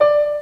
questa breve nota di pianoforte (Re4) vista:
3. (in basso) come fft istantanea che evidenzia le componenti armoniche e un po' di rumore
La figura mostra che anche una breve nota, che all'ascolto può sembrare relativamente statica, è in realtà un fenomeno molto complesso in costante evoluzione nel tempo secondo leggi fisiche.
w24-pno.wav